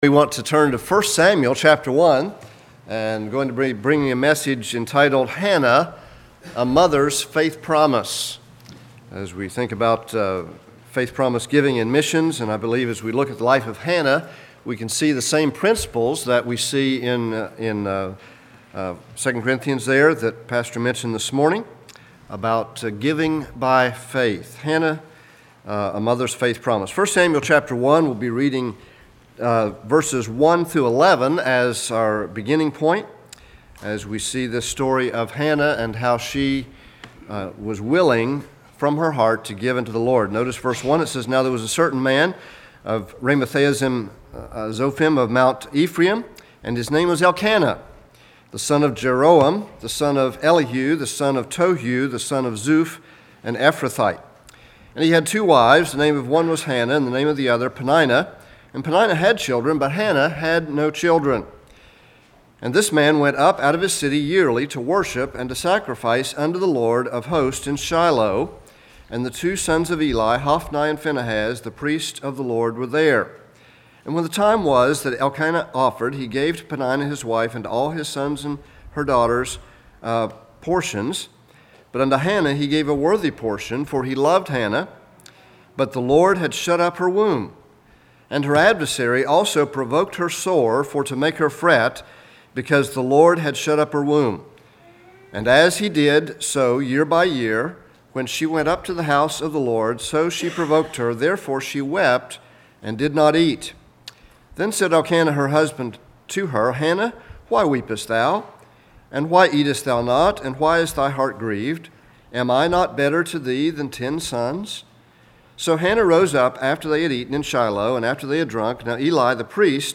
It is our annual missions conference weekend.